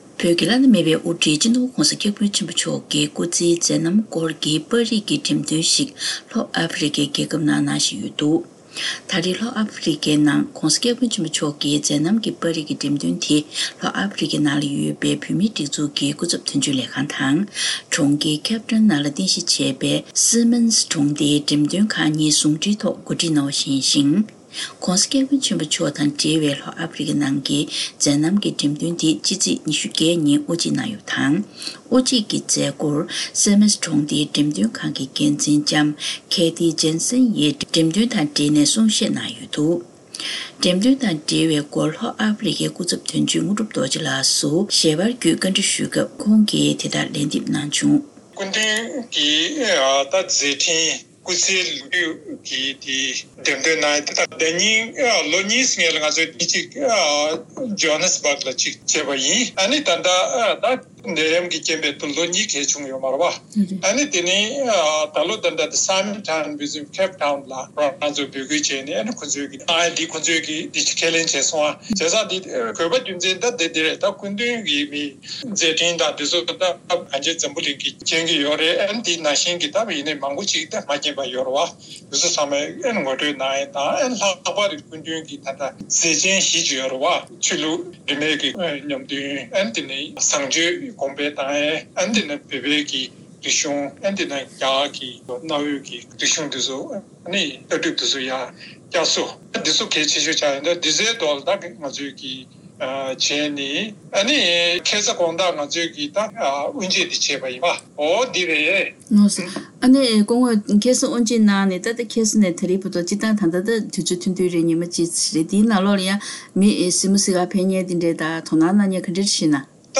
གསར་འགྱུར་དང་འབྲེལ་བའི་ལེ་ཚན་ནང་།